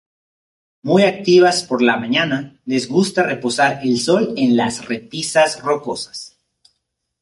/ˈsol/